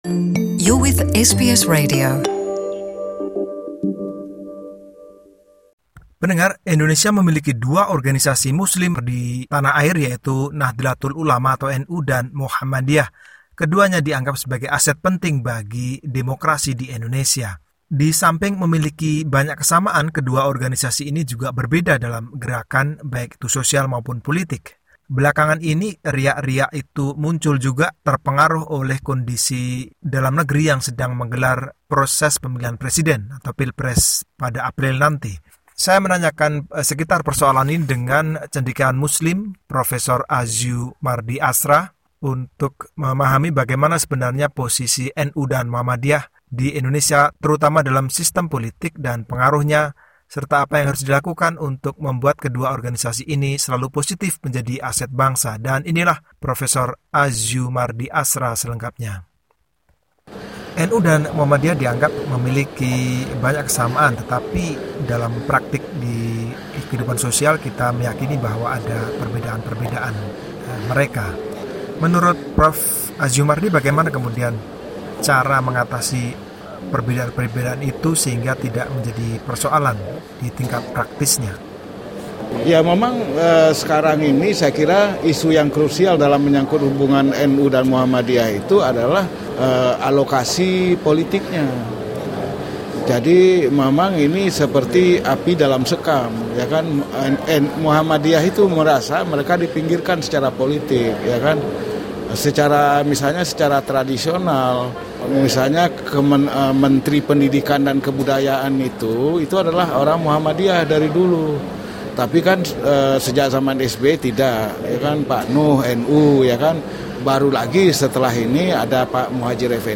Professor Azyumardi Azra, Professor Senior dalam Sejarah dan Kebudayaan Islam di Syarif Hidayatullah State Islamic University of Jakarta, seorang intelektual Muslim berbicara tentang NU dan Muhammadiyah sebagai organisasi Muslim terbesar di Indonesia.